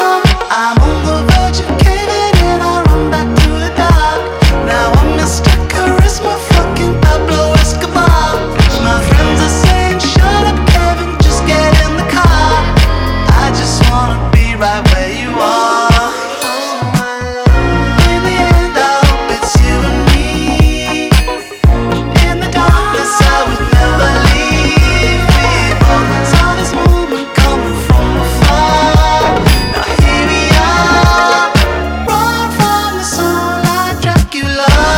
Жанр: Танцевальные / Альтернатива